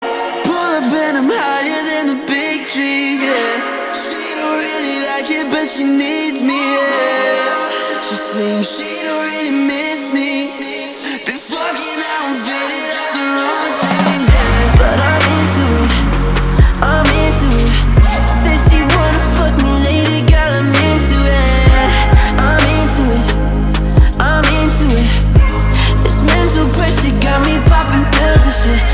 альтернатива
битовые